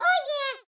Baby Mario gets selected in Mario Kart 8.
MK8_Baby_Mario_-_Character_select.oga